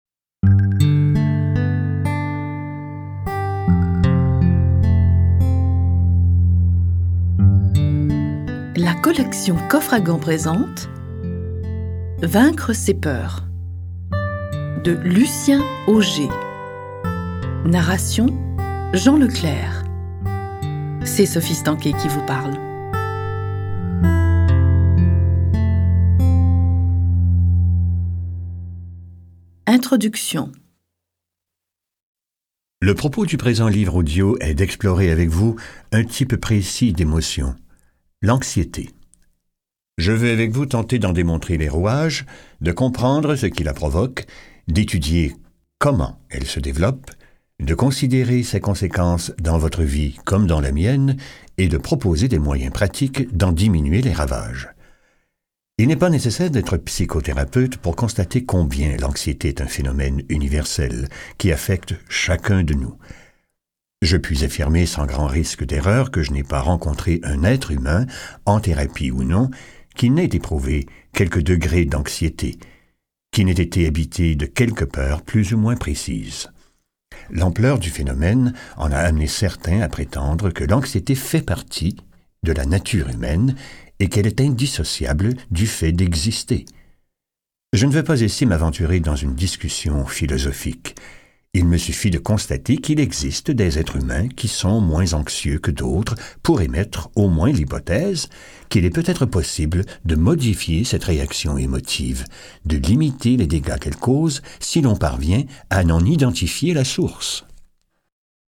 Ce livre audio offre au lecteur une meilleure compréhension de ces phénomènes et présente une méthode pour les combattre et s’en défaire.